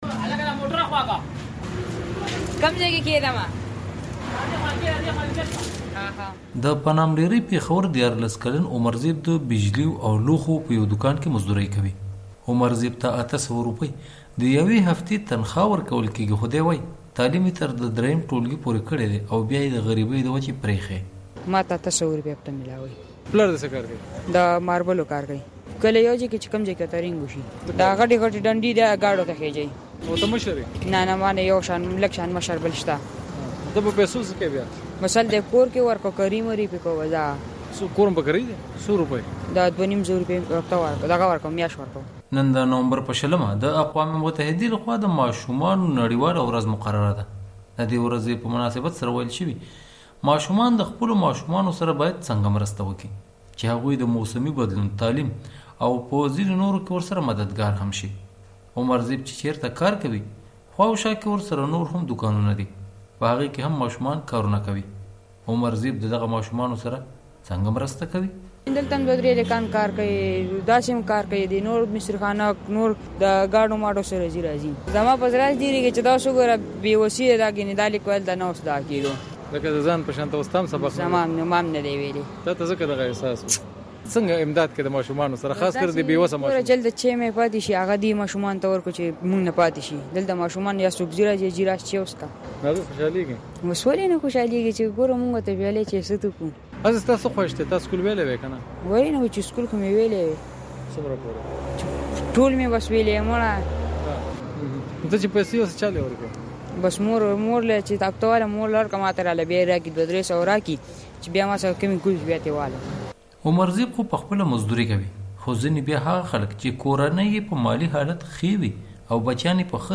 د مشال راډیو دا ځانګړی رپورټ په دوکان کې د کارکوونکو ماشومانو په غږ پیلېږي.